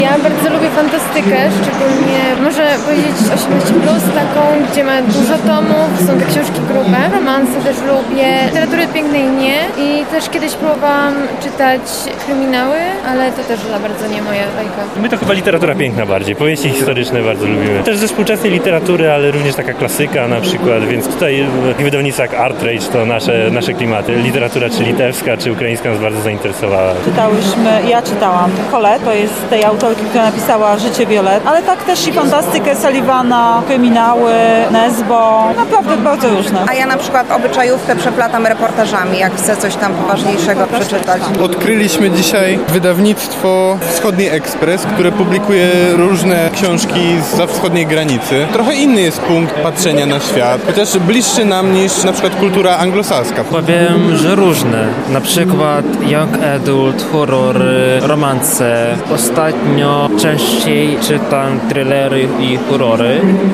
Zapytaliśmy uczestników Lubelskich Targów Książki, po jakie gatunki sięgają i co ich najbardziej interesuje.